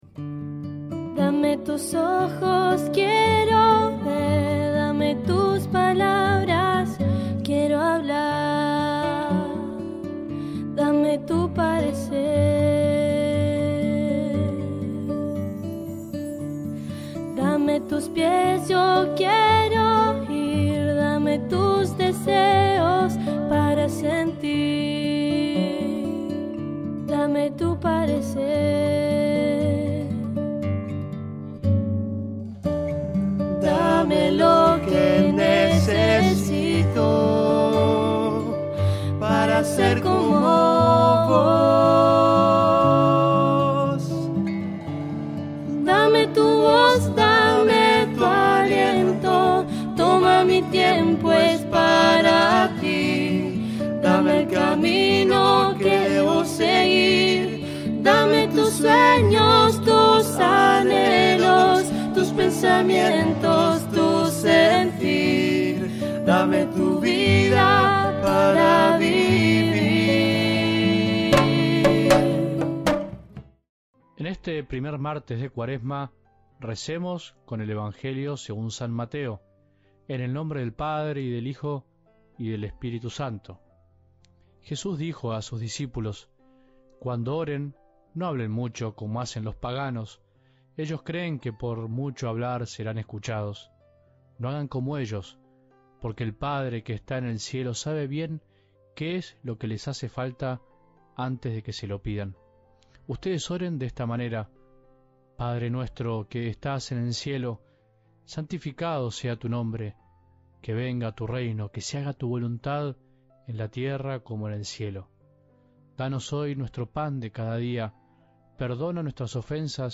Primera lectura